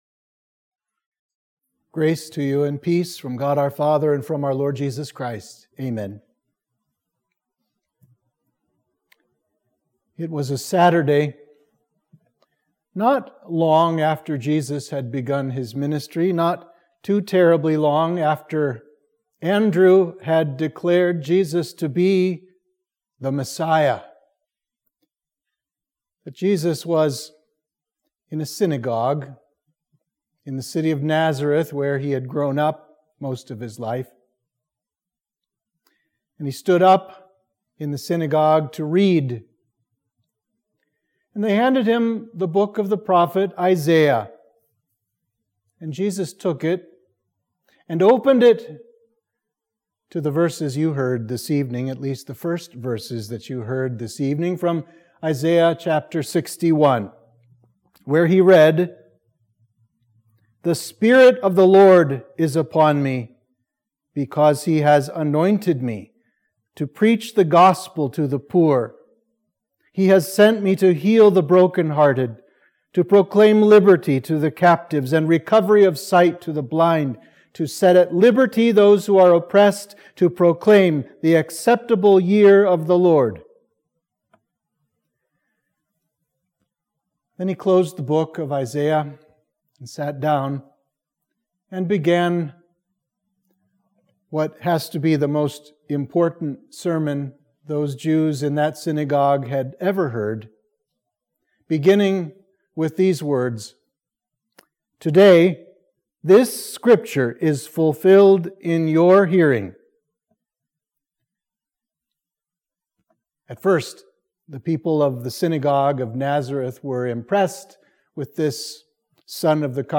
Sermon for Midweek of Trinity 19